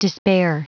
Prononciation du mot despair en anglais (fichier audio)
Prononciation du mot : despair